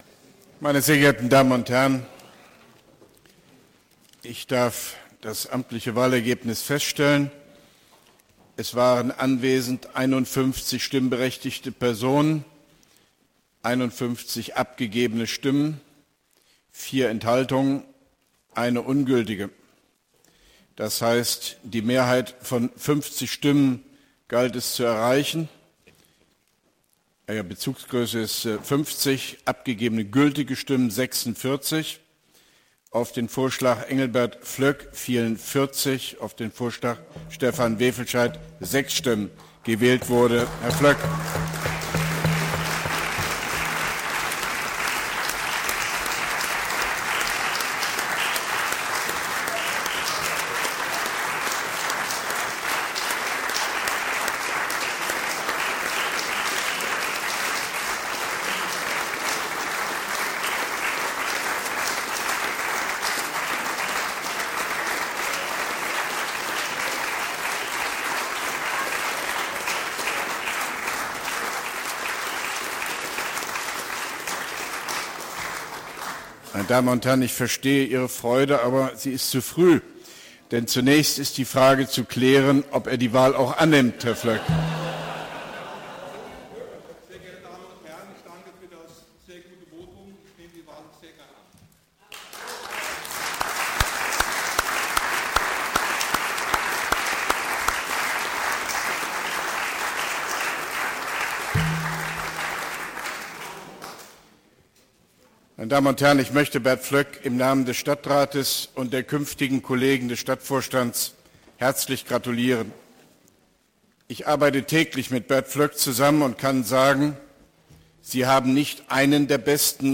(2) Koblenzer Stadtratssitzung 21.04.2016: Rede von OB Hofmann-Göttig zur Wahl des neuen Baudezernenten Bert Flöck